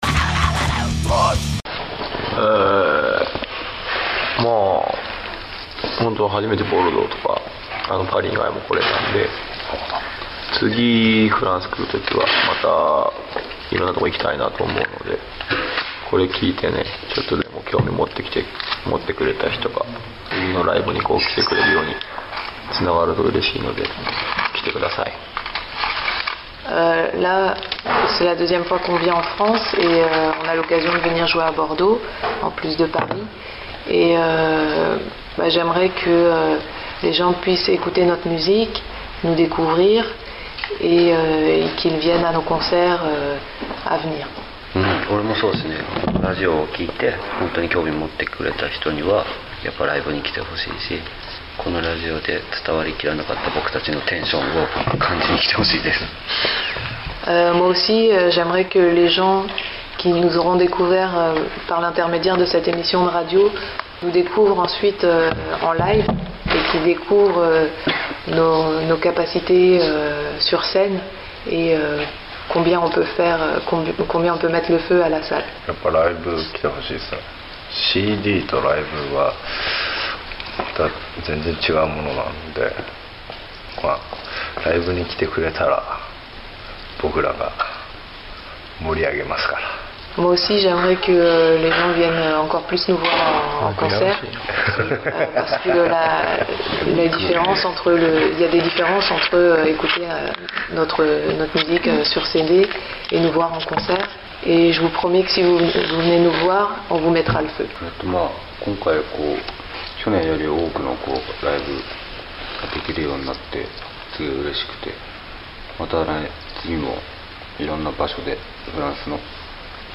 La fin de l'interview en version MP3
interview mucc.mp3